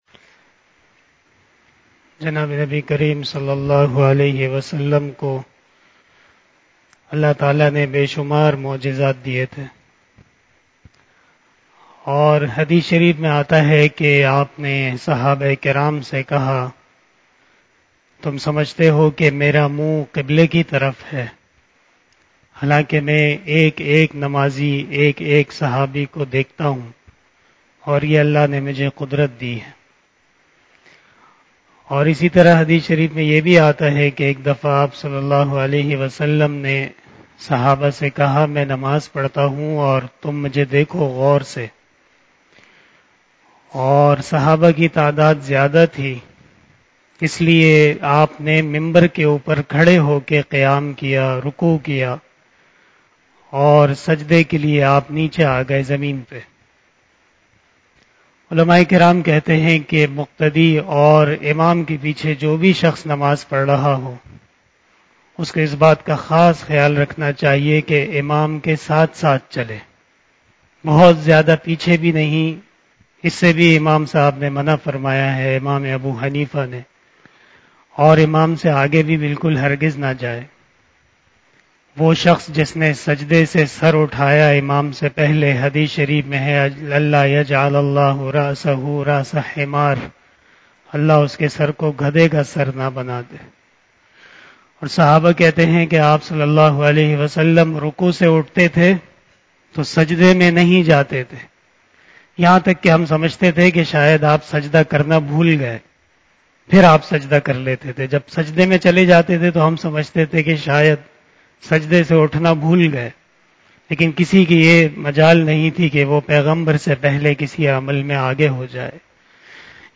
086 After Asar Namaz Bayan 22 September 2022 (25 Safar 1444HJ) Thursday